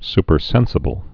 (spər-sĕnsə-bəl)